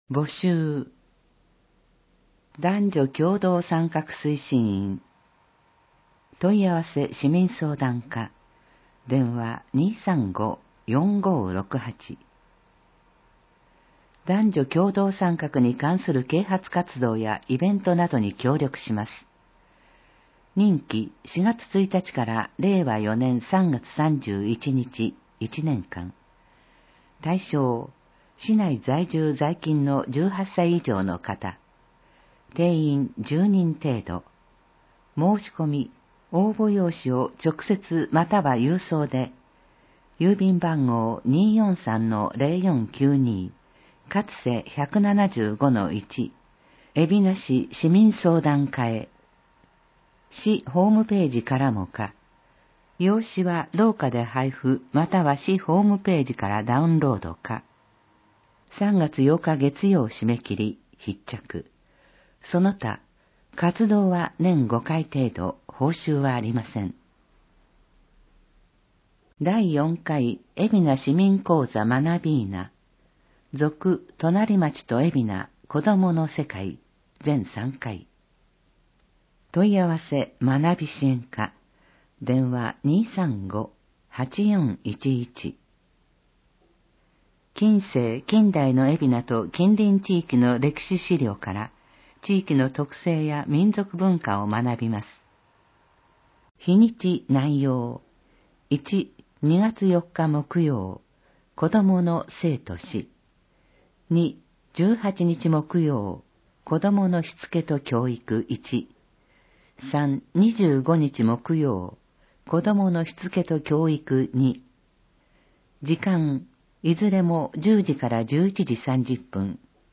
広報えびな 令和3年1月15日号（電子ブック） （外部リンク） PDF・音声版 ※音声版は、音声訳ボランティア「矢ぐるまの会」の協力により、同会が視覚障がい者の方のために作成したものを登載しています。